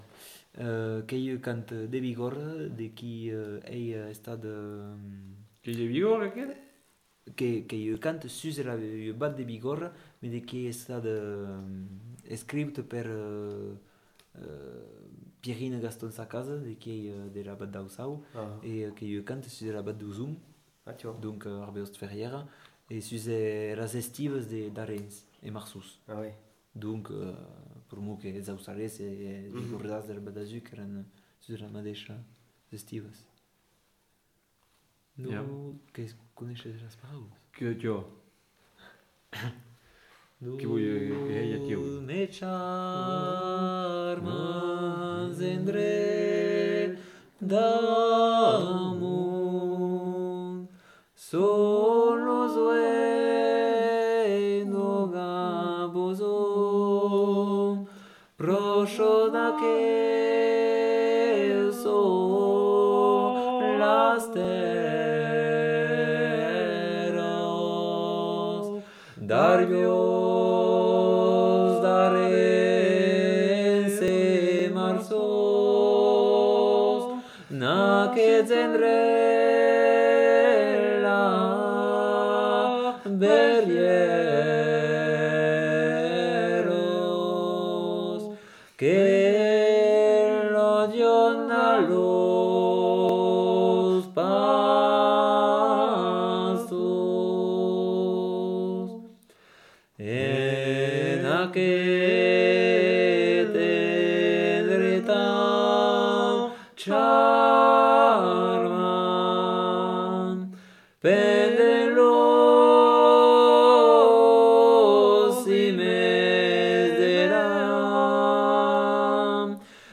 Aire culturelle : Bigorre
Lieu : Bénac
Genre : chant
Effectif : 2
Type de voix : voix d'homme
Production du son : chanté
Descripteurs : polyphonie